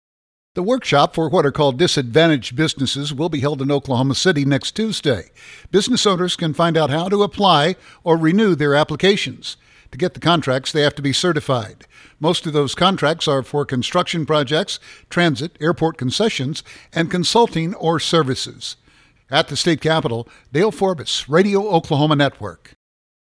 provide details